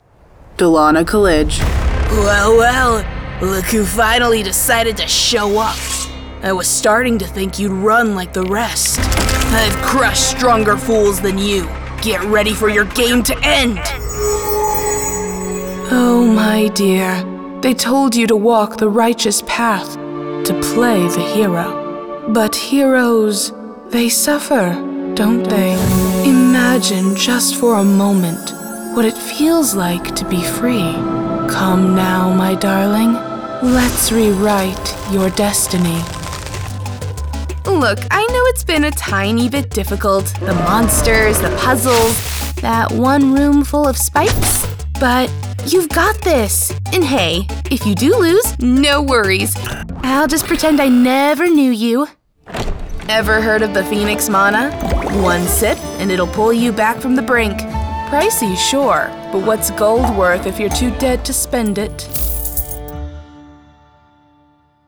Video Game Reel
American, Southern American